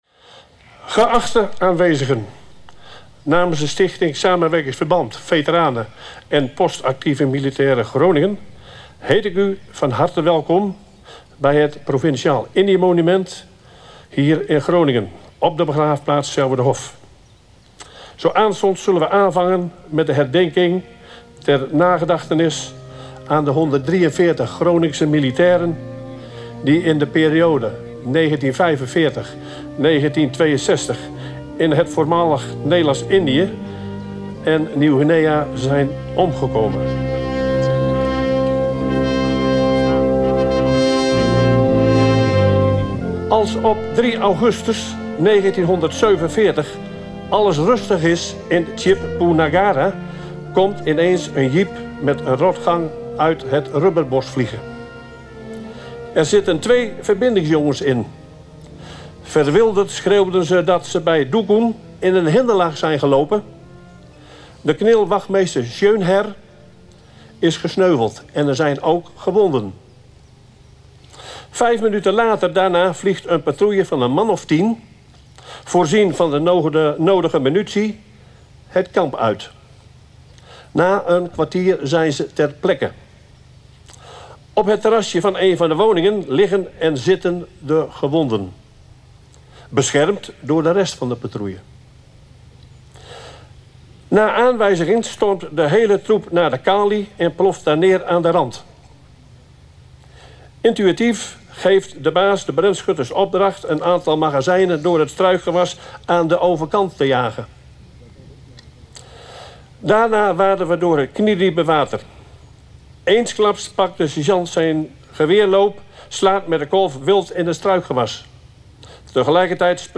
Honderden mensen waren dinsdagmiddag aanwezig op begraafplaats het Selwerderhof voor de jaarlijkse Indië-herdenking. Bij de herdenking werden de 132 Groningse militairen herdacht die om het leven kwamen in de voormalige Nederlandse kolonie.
De slachtoffers werden dinsdagmiddag herdacht bij het Provinciaal Indië Monument op het Selwerderhof. Behalve toespraken, werden er gedichten voorgelezen door leerlingen van basisschool De Pendinghe en werden er kransen gelegd.